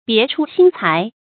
注音：ㄅㄧㄝ ˊ ㄔㄨ ㄒㄧㄣ ㄘㄞˊ
別出心裁的讀法